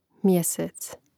mjȅsēc mjesec